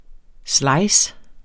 Udtale [ ˈslɑjs ]